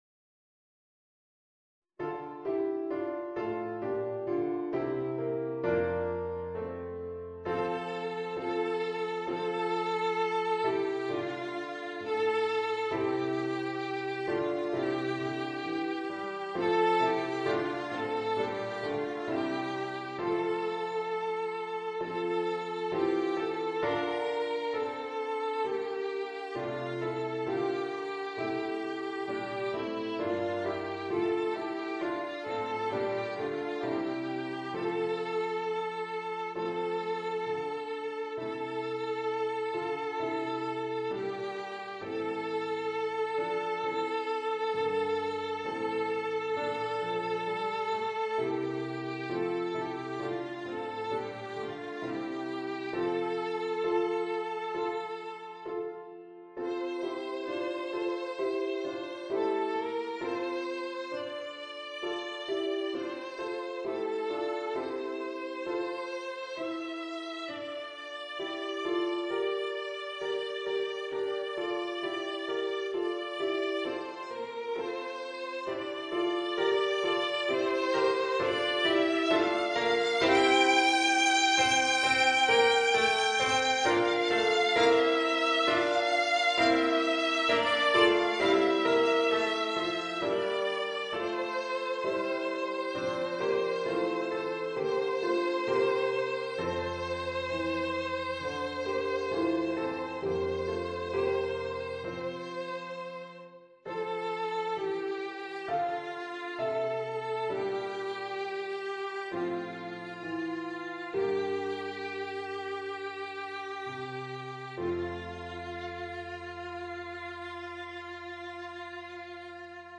Violine & Klavier